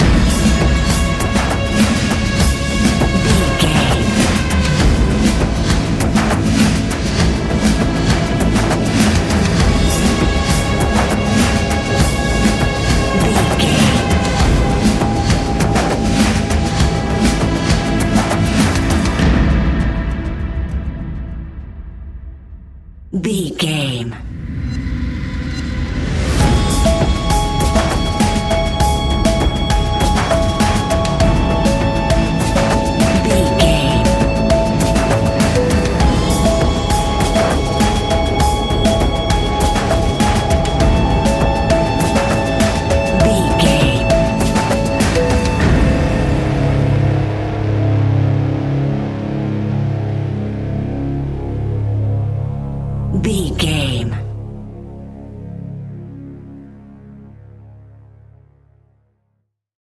Fast paced
In-crescendo
Ionian/Major
C♯
industrial
dark ambient
EBM
synths
Krautrock